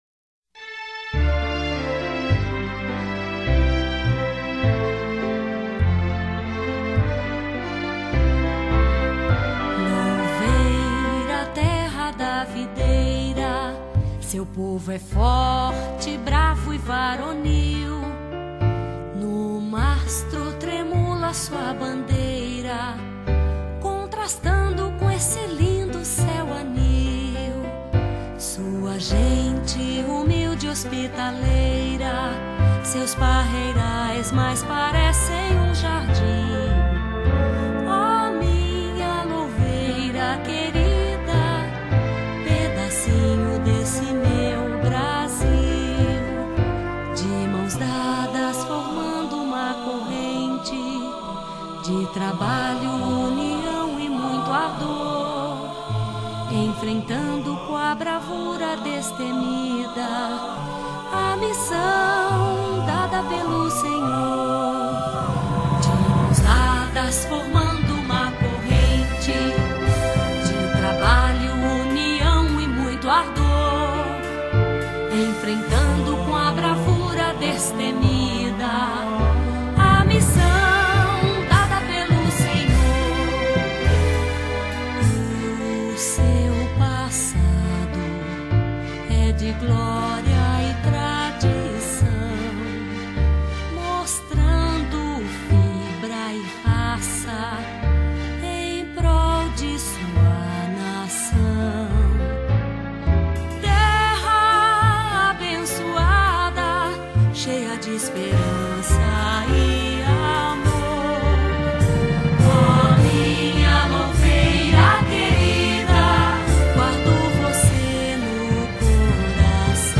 Hino Municipal de Louveira - Cantado